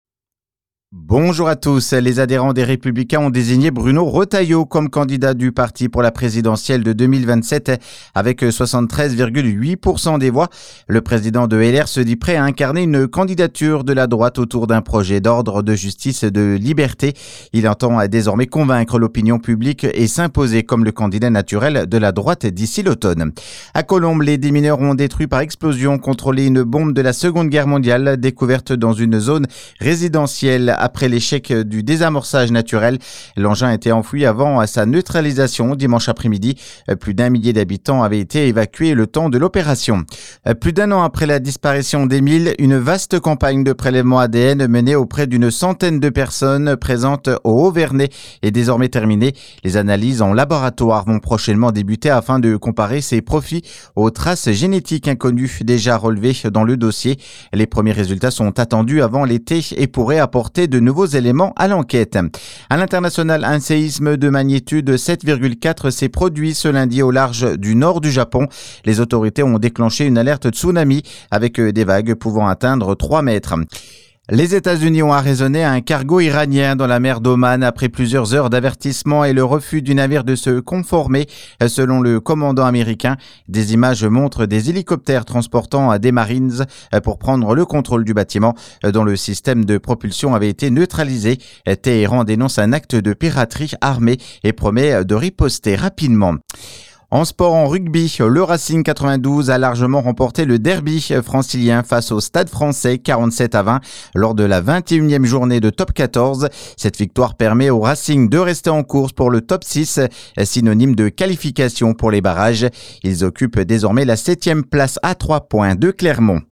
Flash infos 20/04/2026